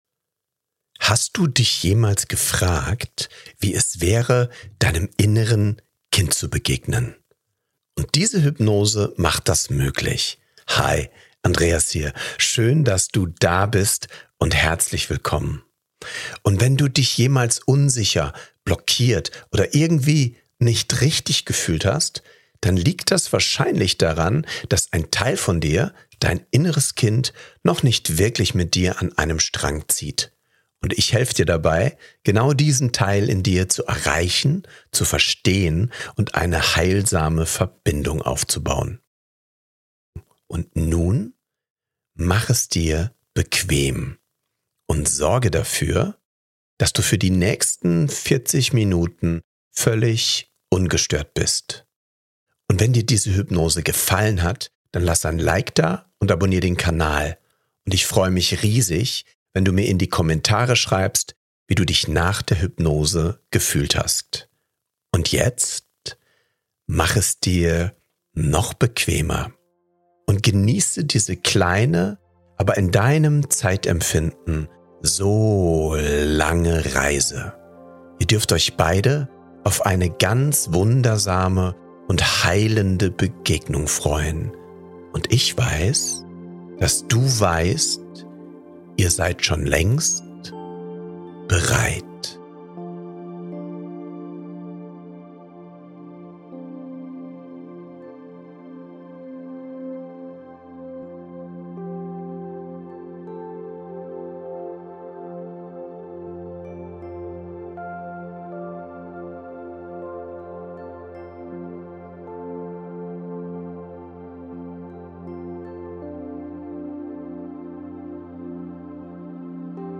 INNERES KIND HEILEN | Geführte Meditation für Selbstliebe & innere Heilung ~ Happiness Mindset Podcast
In dieser geführten Hypnose geht es nicht um Theorie – sondern um echte Verbindung. Du reist an einen sicheren Ort, begegnest deinem inneren Kind und schenkst ihm genau das, was es früher so sehr gebraucht hätte: Wertschätzung.